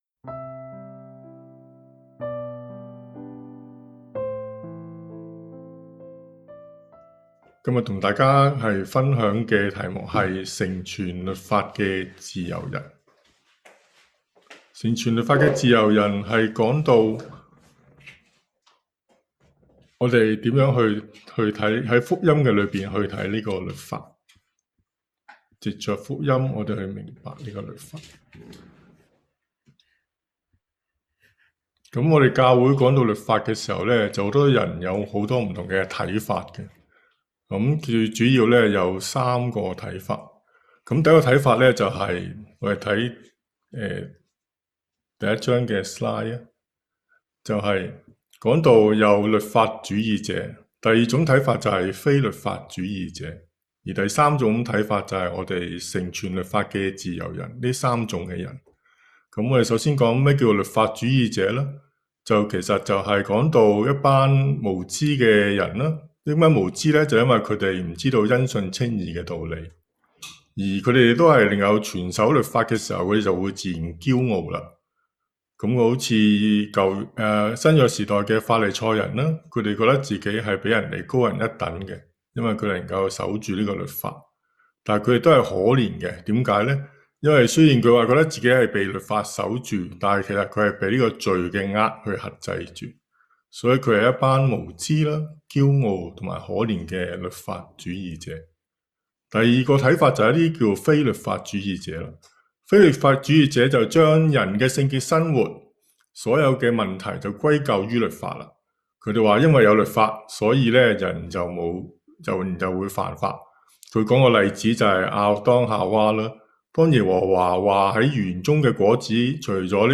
成全律法的自由人（粤语）[1月3日崇拜]
崇拜